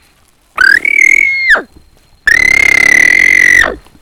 동물소리흉내02.ogg